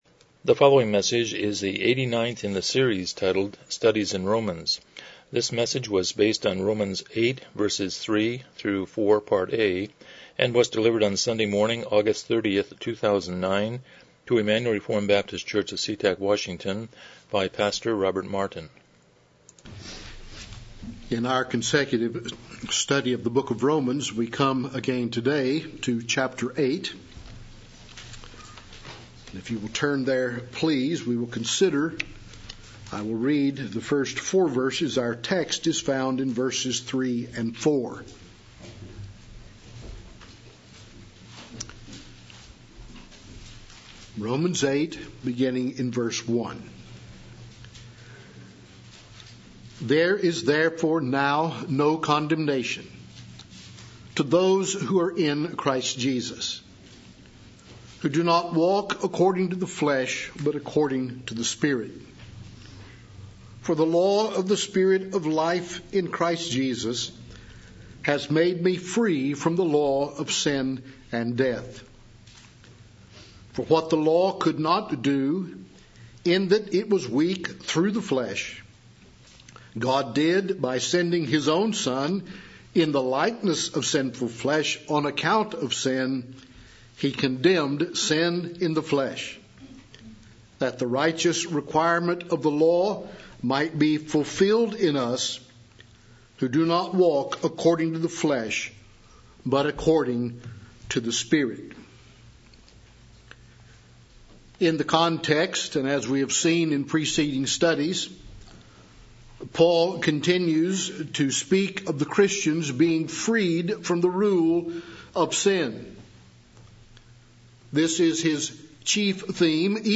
Passage: Romans 8:3-4 Service Type: Morning Worship